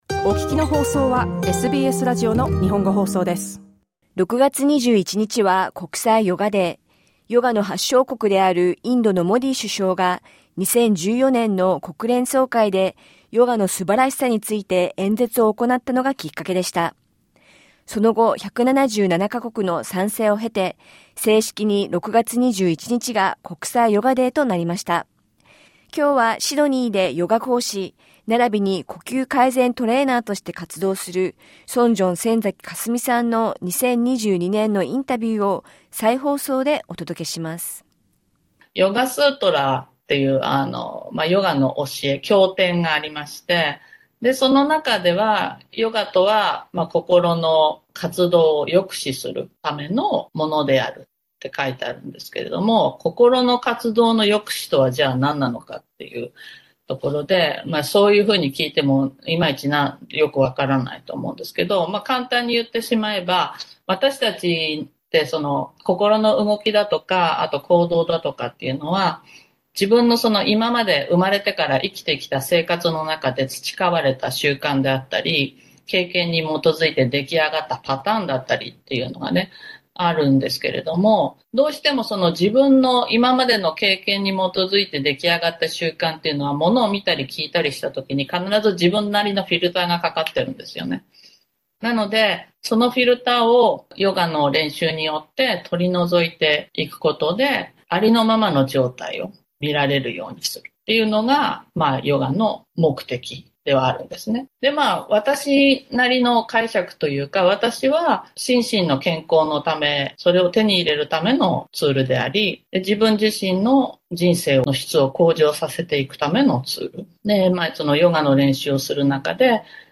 フルインタビューはポッドキャストから。